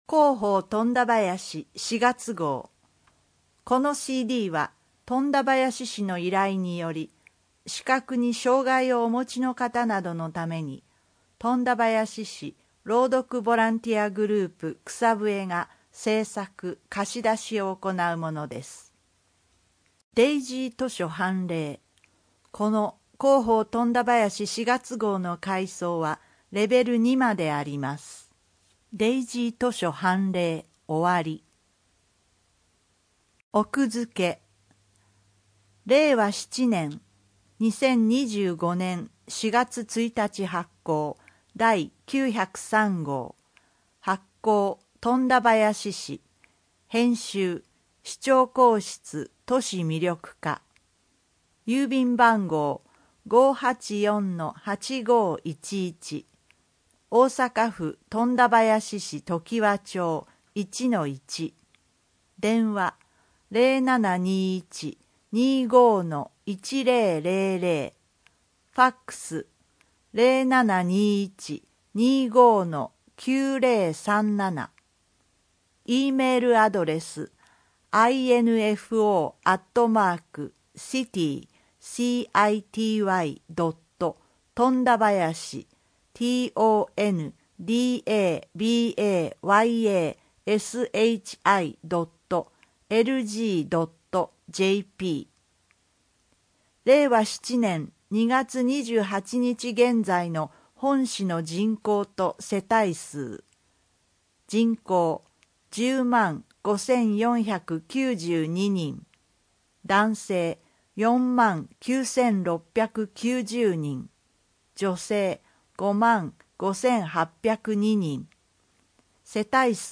この音声は、本市の依頼により富田林市朗読ボランティアグループ「くさぶえ」が視覚に障がいをお持ちの人などのために製作しているものです（図やイラストなど一部の情報を除く）。